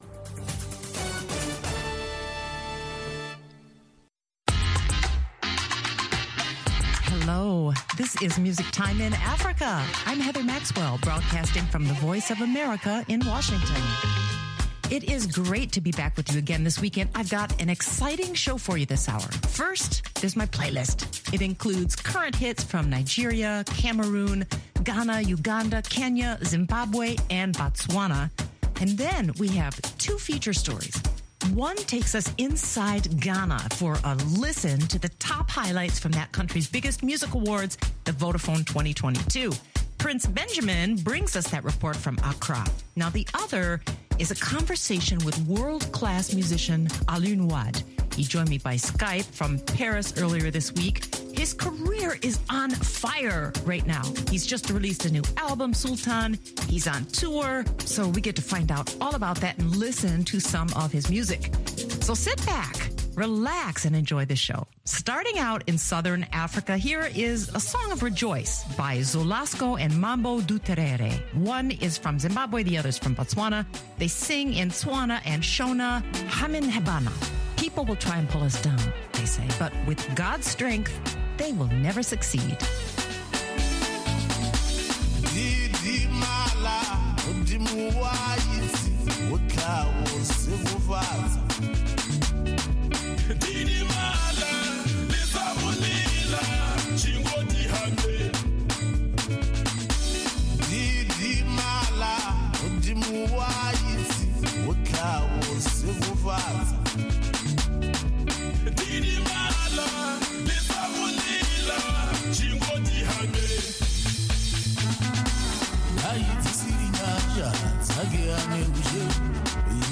Skype conversation